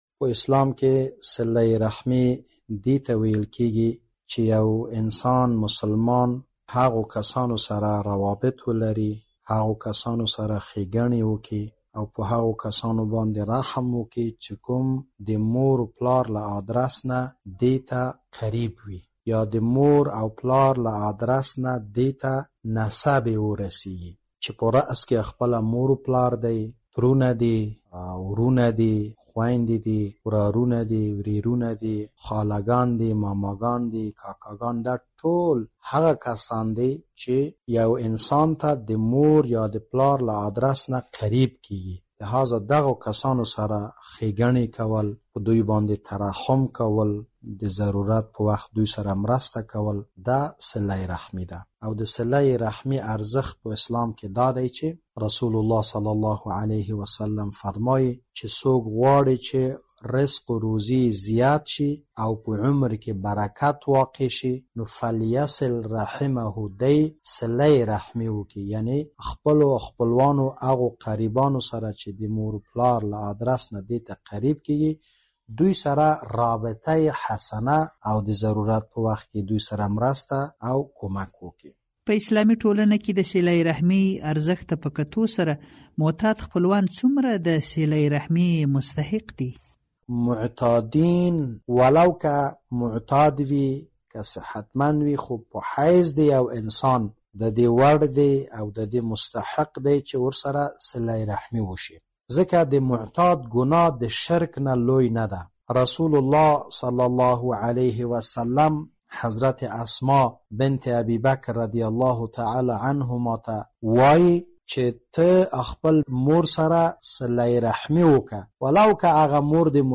مفتي سره مرکه